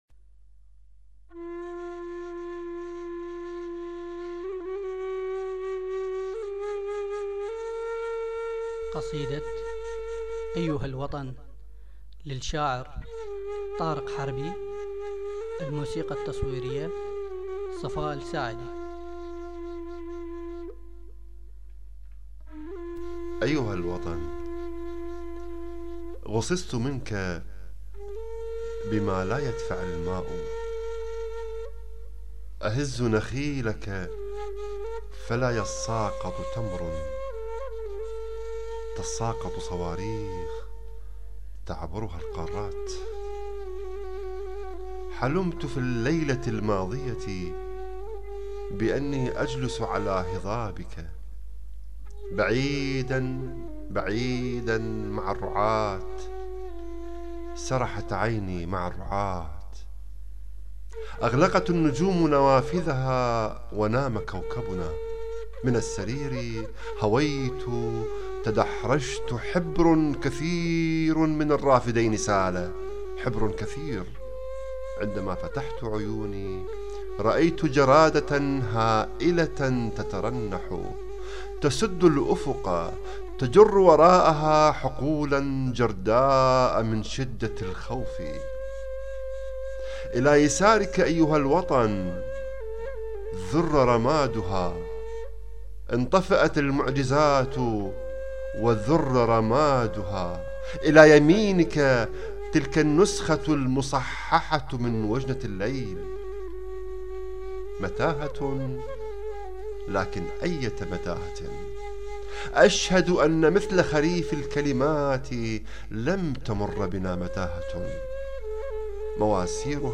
قصيدة (ايها الوطن
الموسيقى  التصويرية